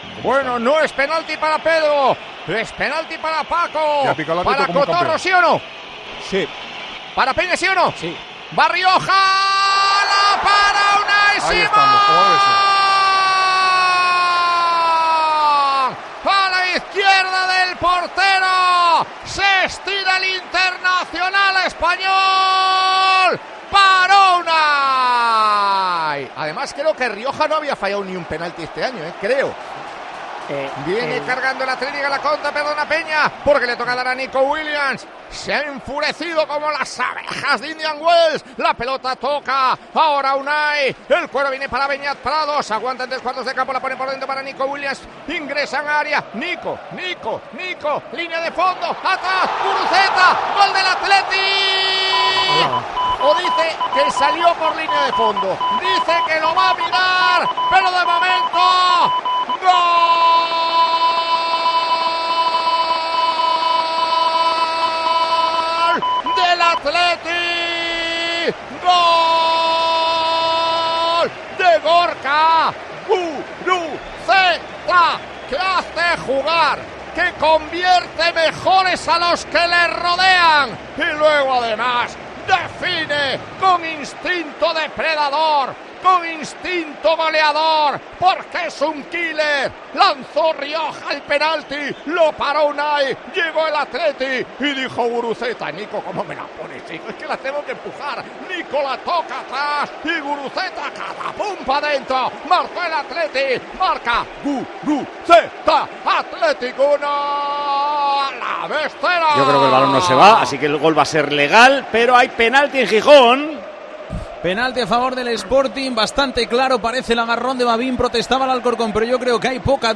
ASÍ TE HEMOS CONTADO EN TIEMPO DE JUEGO LA VICTORIA DEL ATHLETIC
Con Paco González, Manolo Lama y Juanma Castaño